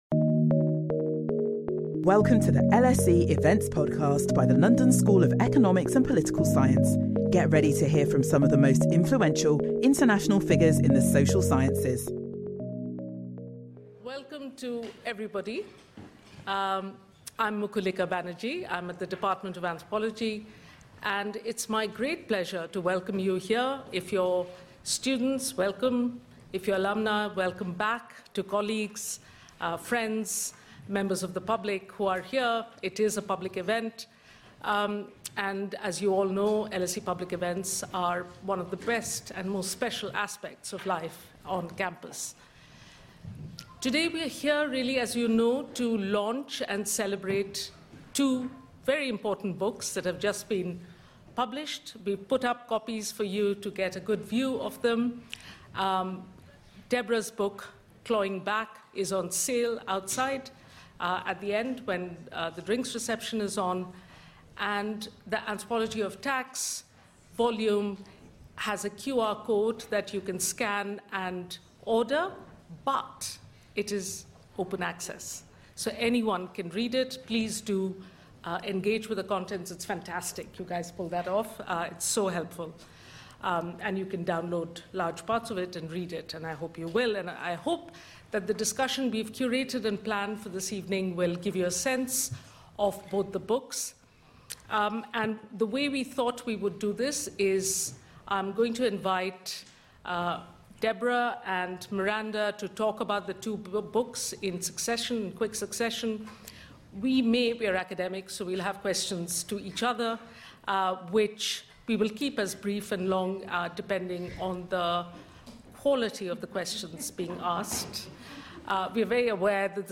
In this panel discussion, anthropologists working on redistribution and tax will present the findings of—and interrogate each other on—two recent books: Clawing Back: redistribution in precarious times, and Anthropology and Tax: ethnographies of fiscal relations.